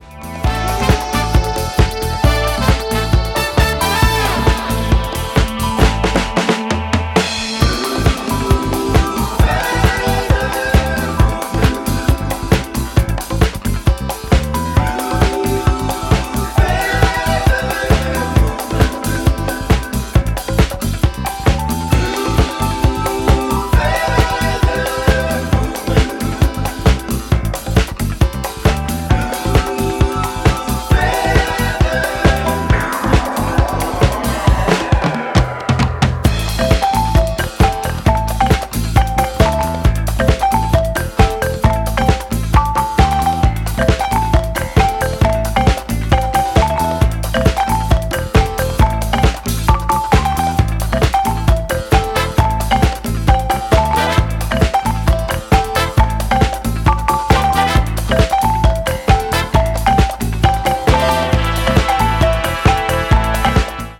and bolstered throughout by a dedicated brass section.